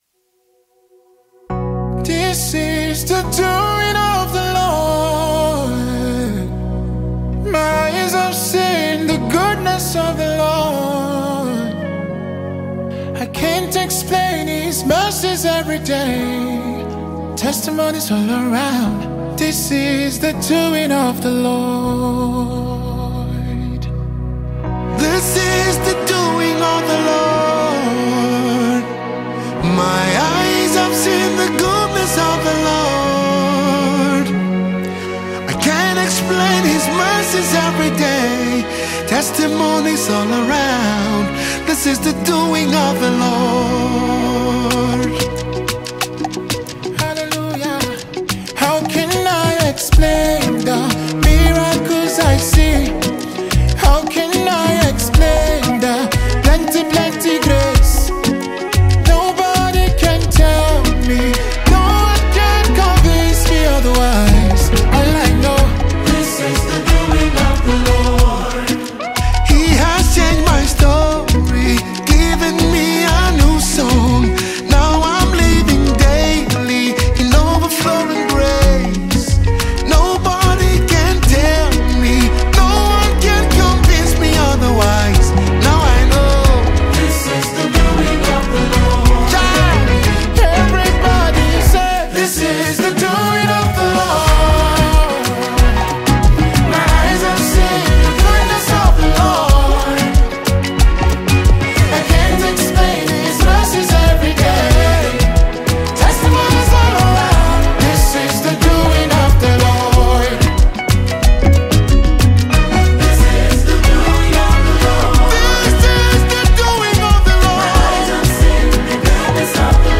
Nigeria talented gospel music singer and songwriter